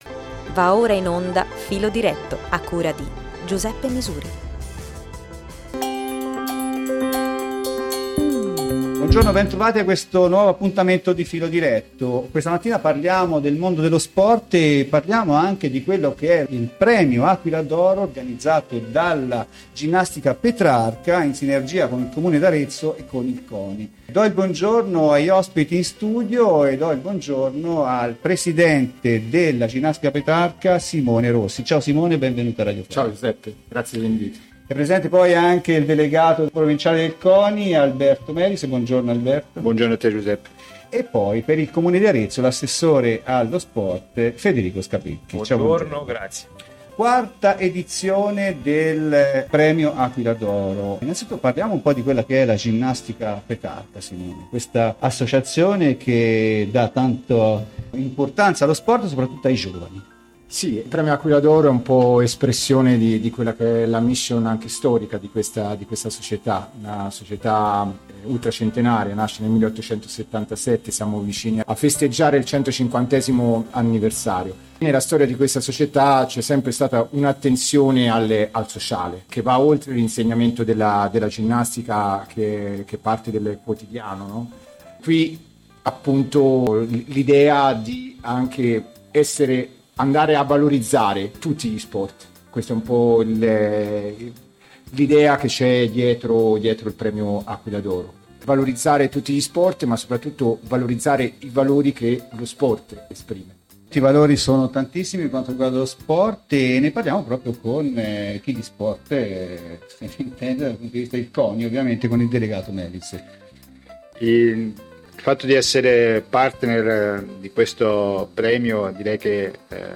Ce ne parlano in studio